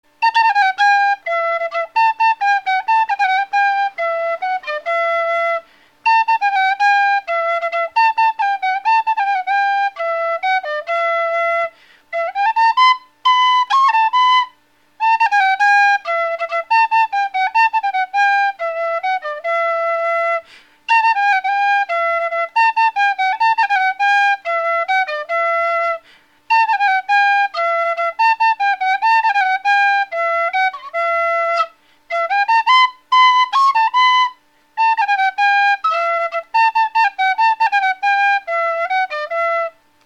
На вистле: продольная флейта